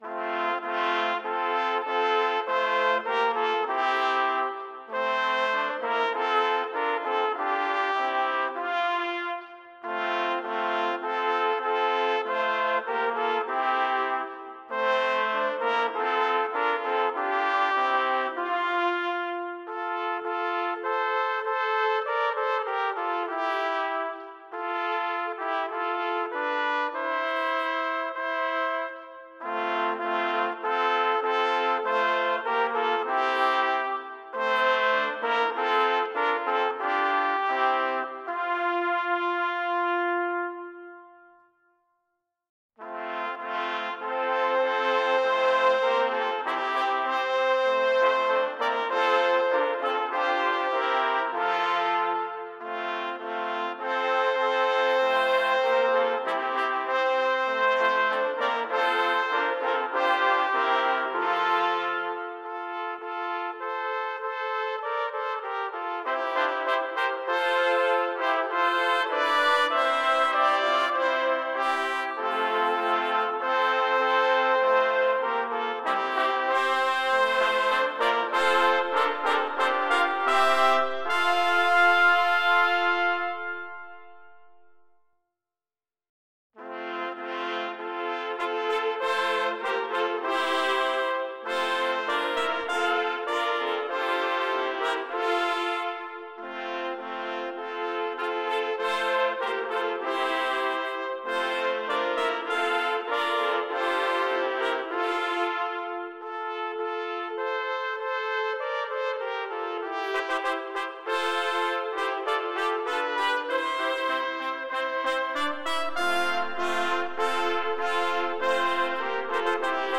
Number of Trumpets: 3
Exhilarating, majestic, and inspiring.